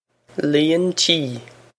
líon tí lee-on chee
Pronunciation for how to say
This is an approximate phonetic pronunciation of the phrase.